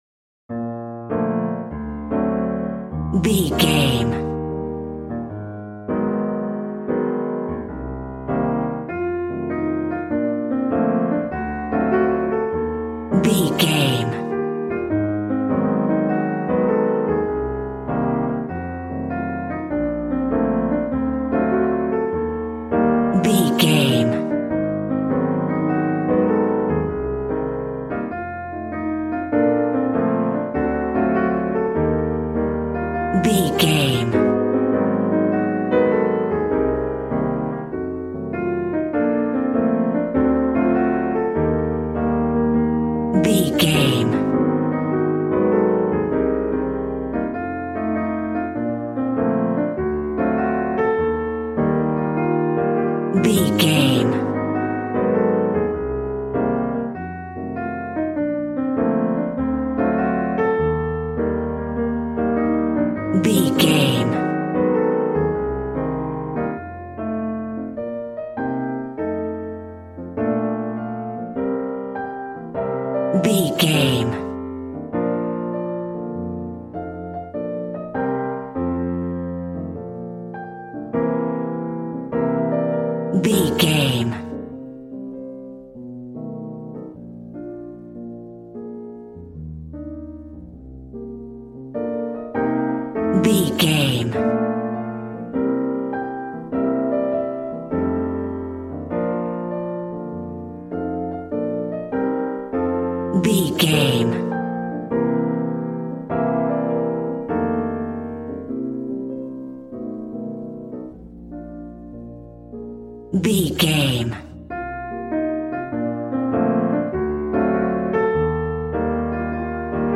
Smooth jazz piano mixed with jazz bass and cool jazz drums.,
Ionian/Major
B♭
smooth
piano
drums